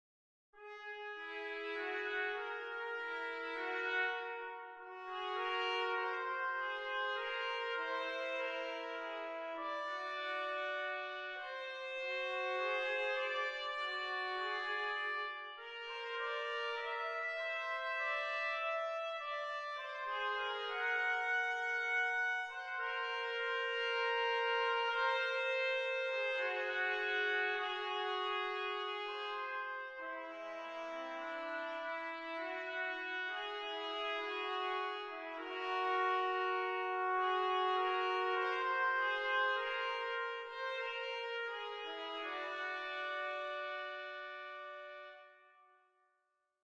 Things to Play on a Boring Day (13 Duets for 2 Trumpets):
A computer generated audio version [Note 27]:
The duet begins with a simple major second which gradually expands, and which increases in rhythmic complexity. The structure is precise and effective.